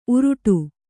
♪ uruṭu